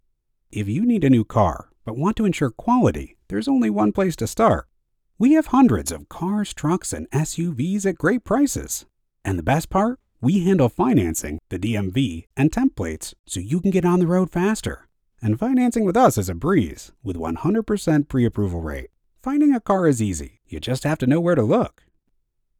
The warm, confident, intelligent voice of the guy next door
Auto Dealership Branding Commercial
Midwestern / Neutral
Middle Aged